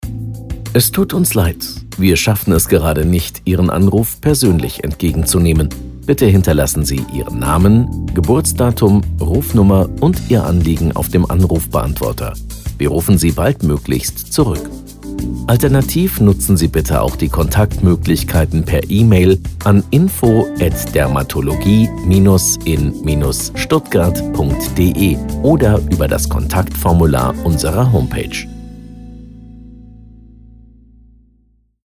Telefonansagen mit echten Stimmen – keine KI !!!
Ansage bei Überlastung: